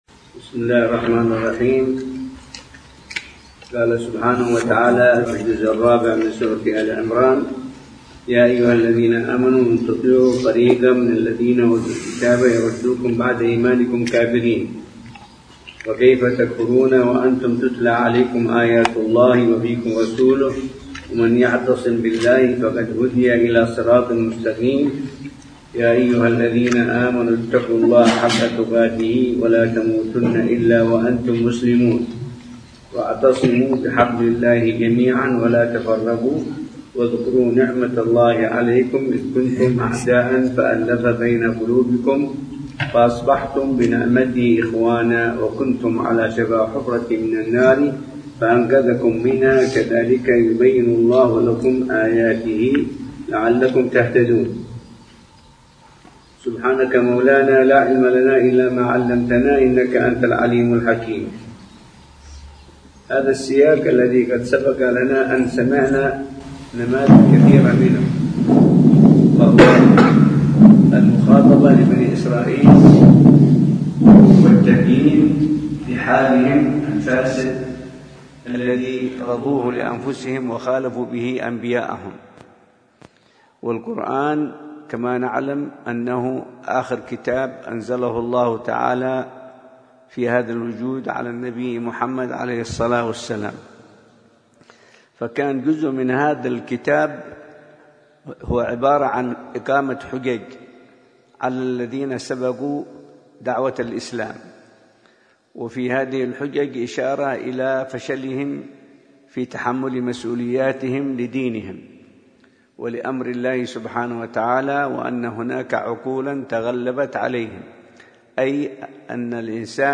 درس التفسير